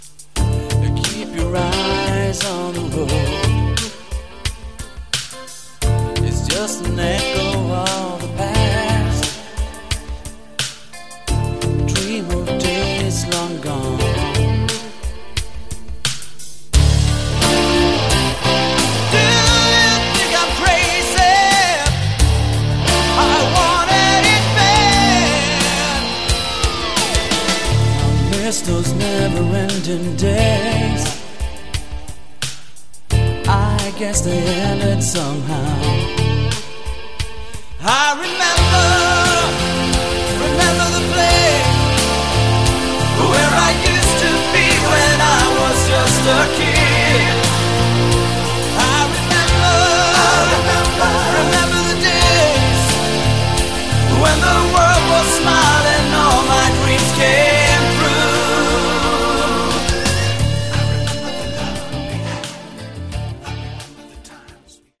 Category: AOR